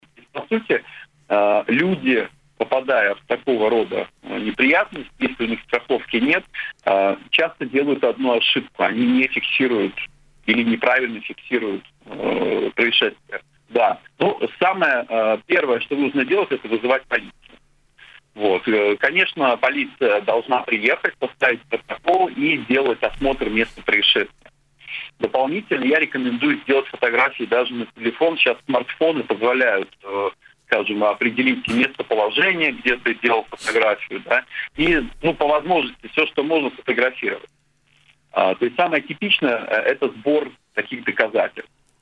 Об этом в эфире радио Baltkom